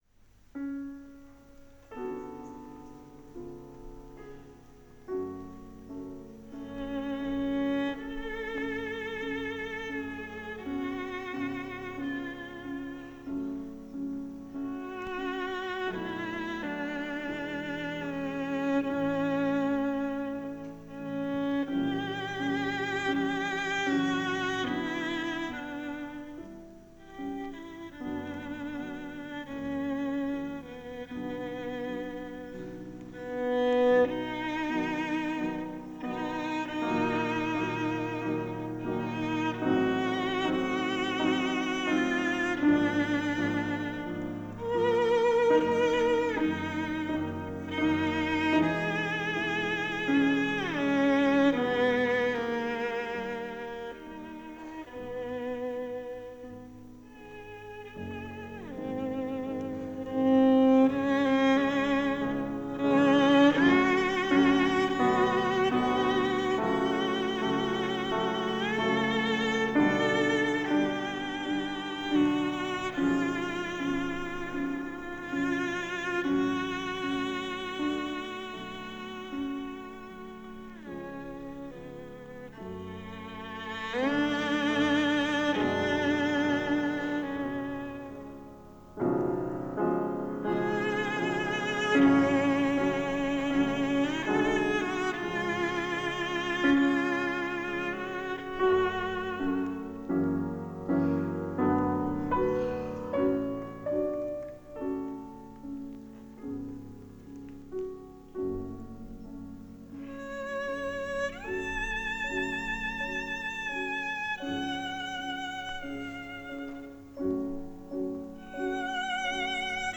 скрипка